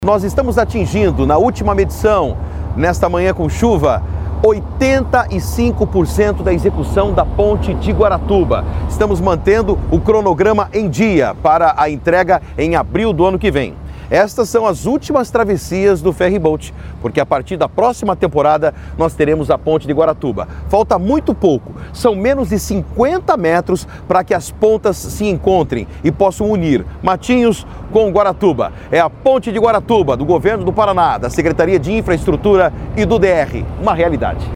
Sonora do secretário de Infraestrutura e Logística, Sandro Alex, sobre o andamento das obras da Ponte de Guaratuba